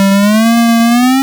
retro_synth_wobble_07.wav